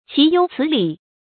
注音：ㄑㄧˇ ㄧㄡˇ ㄘㄧˇ ㄌㄧˇ
豈有此理的讀法